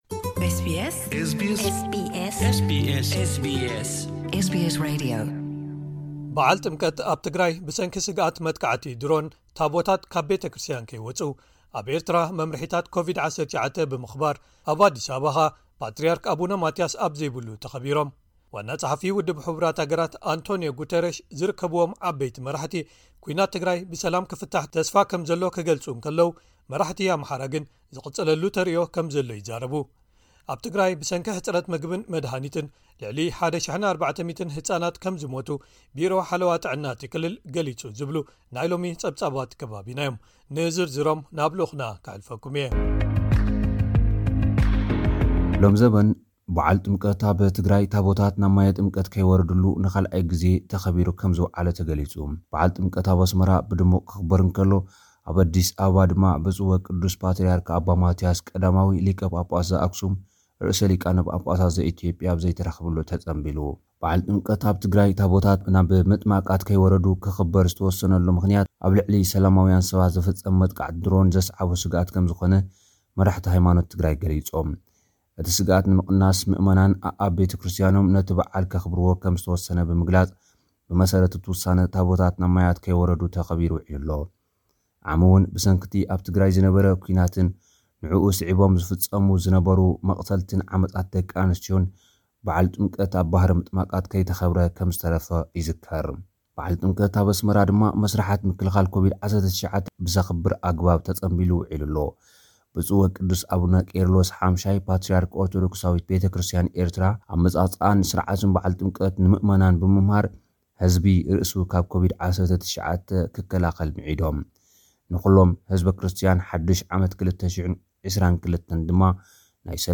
ሓጸርቲ ጸብጻባት ዜና፡